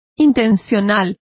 Complete with pronunciation of the translation of intentional.